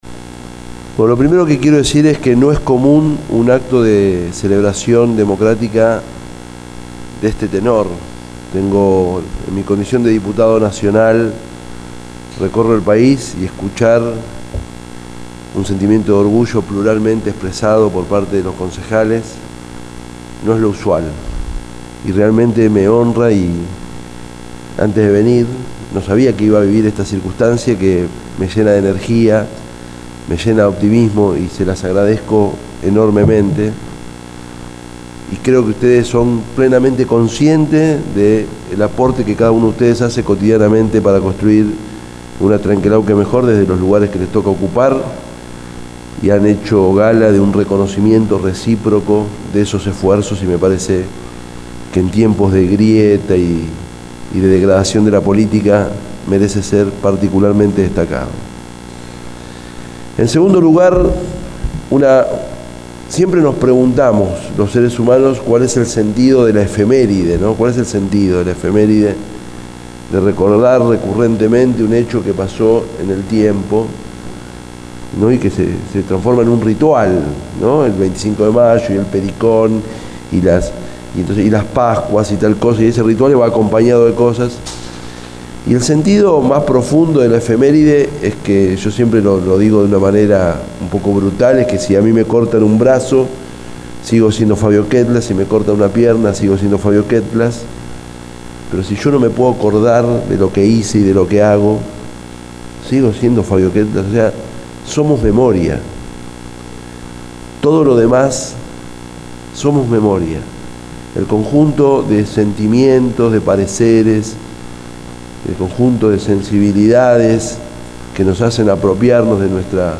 En el Acto por el día de la Restauración de la Democracia, en el Consejo Deliberante se realizó un homenaje a todos los Intendentes que gobernaron desde el año 83 a la fecha y uno de los que tomo la palabra fue el Diputado Nacional Fabio Quetglas.
Fabia-Quetglas-acto-dia-democracia.mp3